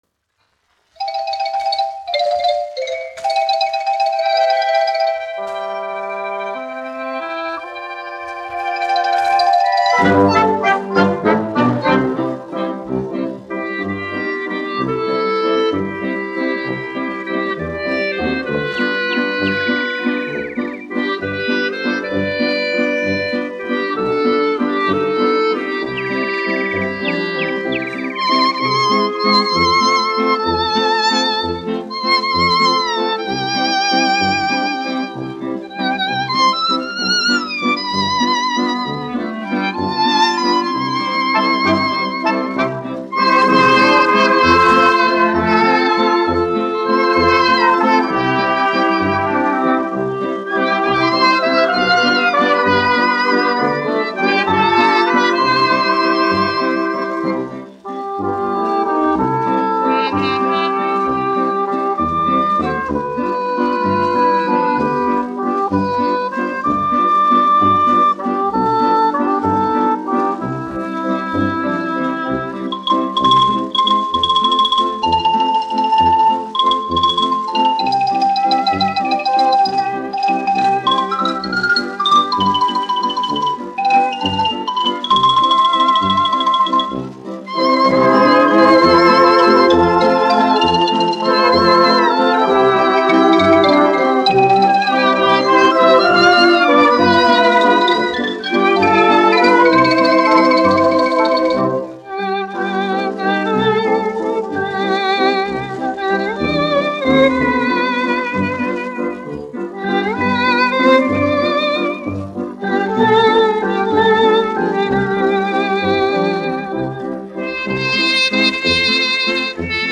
1 skpl. : analogs, 78 apgr/min, mono ; 25 cm
Populārā instrumentālā mūzika
Skaņuplate